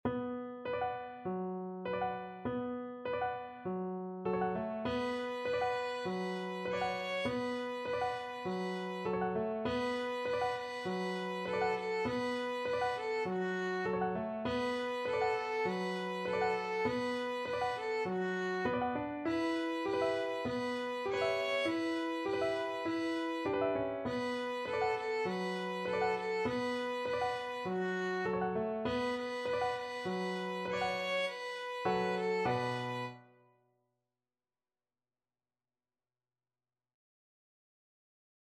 Violin
4/4 (View more 4/4 Music)
B minor (Sounding Pitch) (View more B minor Music for Violin )
Moderato
Traditional (View more Traditional Violin Music)